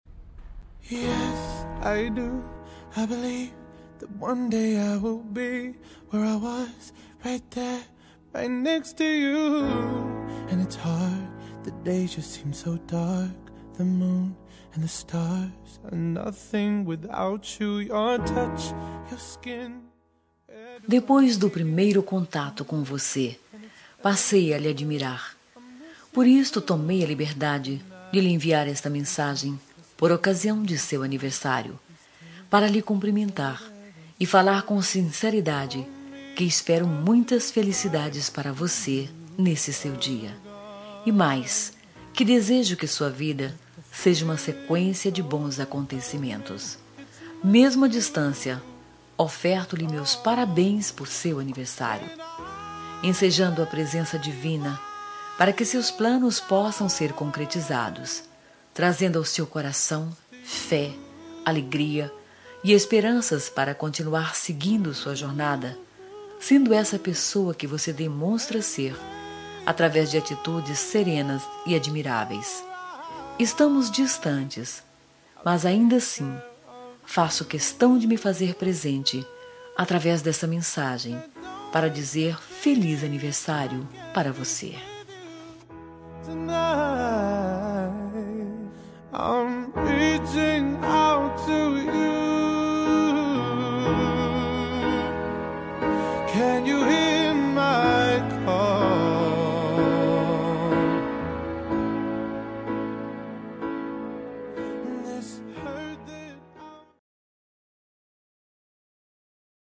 Aniversário Distante – Pessoa Especial – Feminina – Cód: 8892